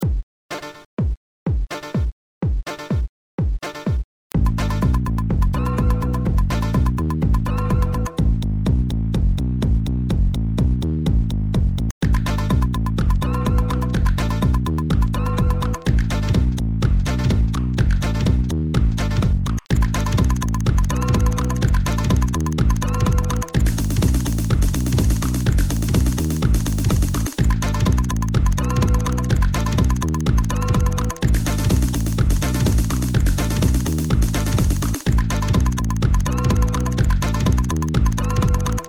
Plastic-house project.
Projet house plastique.